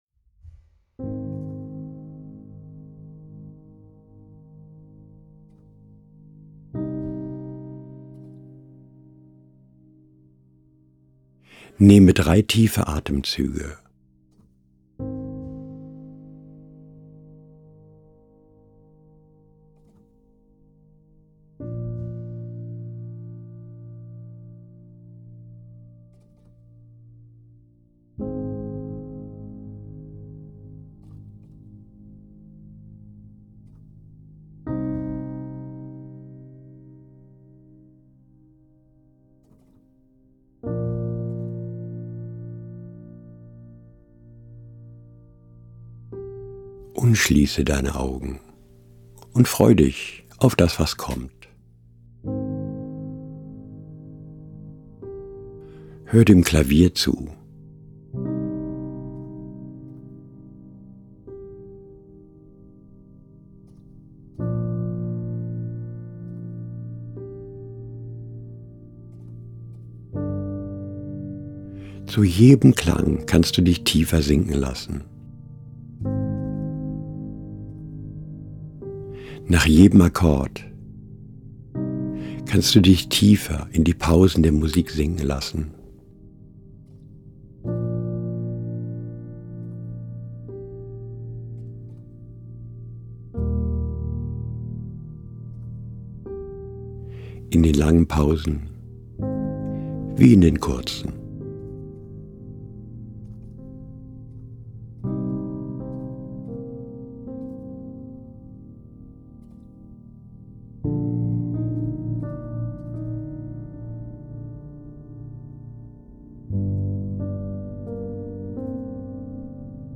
Version zum direkt Einschlafen
.. überwältigendesStrahlen Leichtigkeit Diese Trancerzählung läßt unsere Leichtigkeit nach einer Wanderung wieder zu uns zurückkehren. Ein subtiles Spiel mit der Desintegration und der Integration.
Zielgerichtete, therapeutisch wirksame, immersiv-meditative Erzählungen zum vielfachen Anhören. 12 Minuten, Download mp3- Audio , binaural (mehr hierzu) . Es eignet sich jeder Kopfhörer.